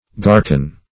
Garcon \Gar`[,c]on"\, n. [F.]